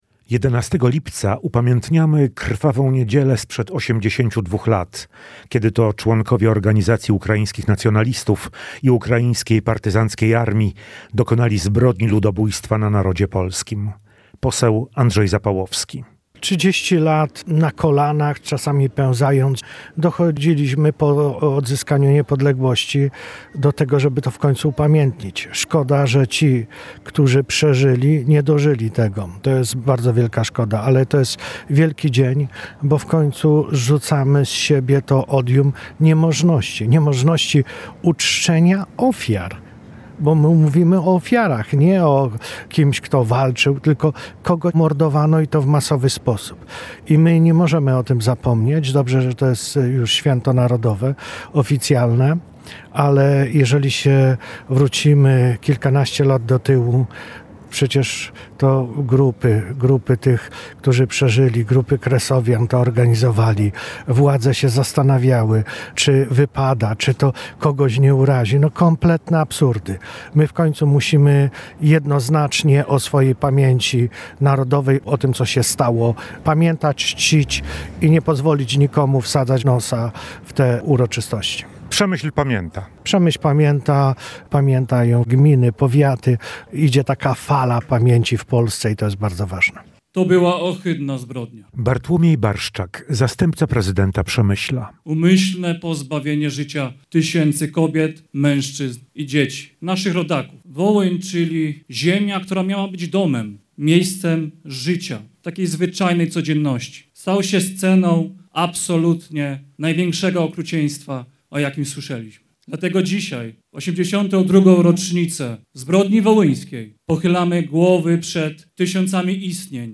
Przemyśl upamiętnił ofiary rzezi wołyńskiej. Główne uroczystości z udziałem Kompanii Honorowej Wojska Polskiego odbyły się przy Grobie Wołyńskim, na Cmentarzu Wojskowym.
Ofiary ludobójstwa uczczono salwą honorową.